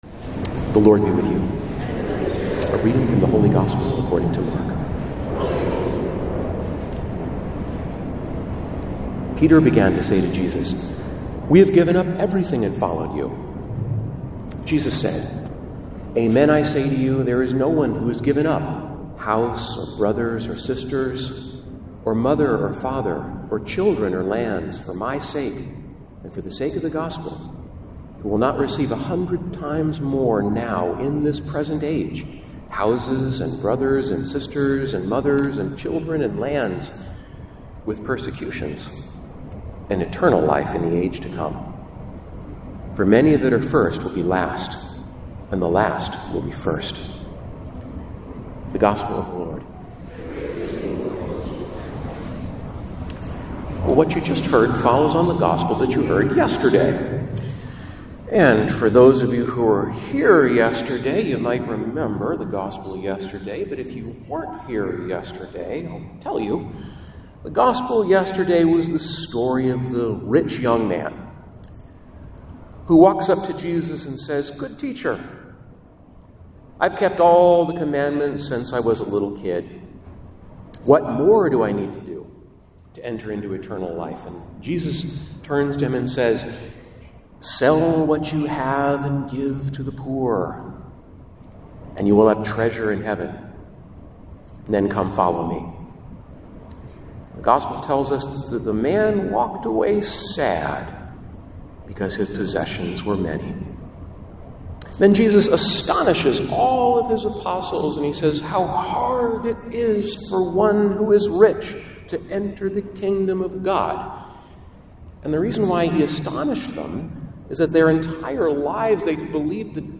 1 Czym wypełniasz Serce? - Homilia VIII Niedzieli Zwykłej 5:58